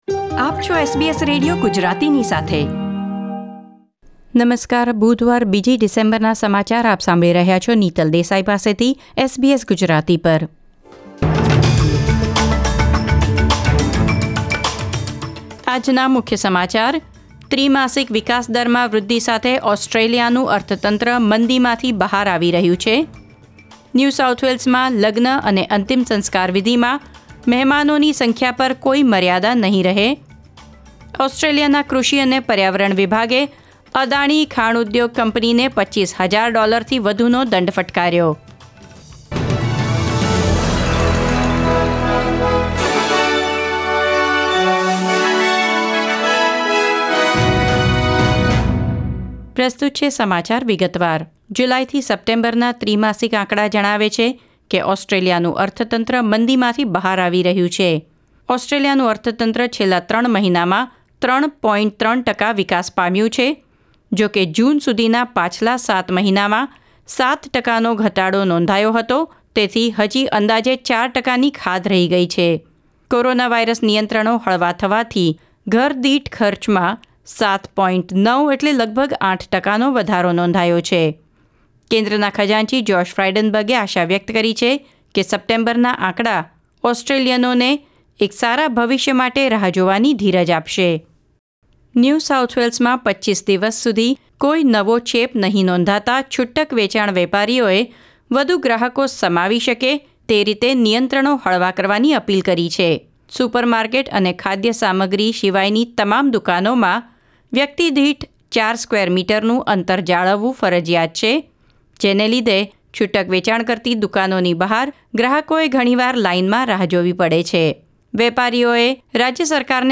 SBS Gujarati News Bulletin 2 December 2020